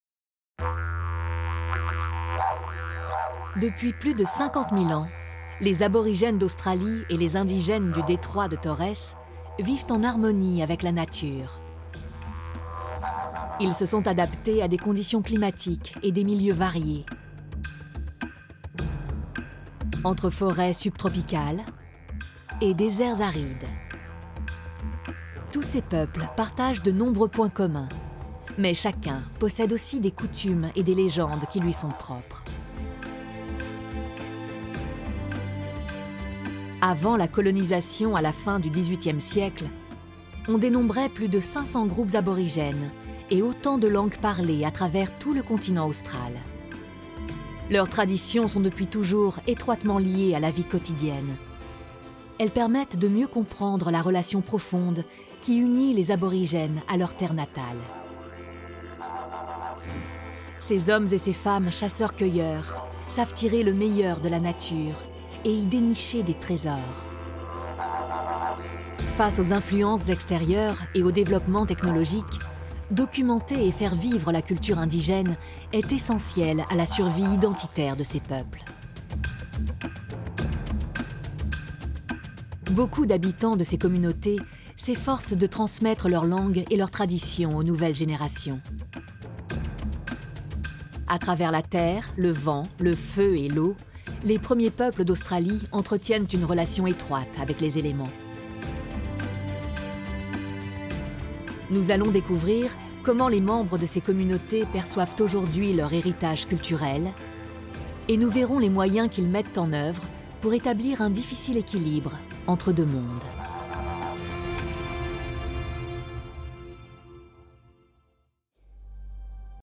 Narration documentaire TERRES ABORIGENES France 5
Voix off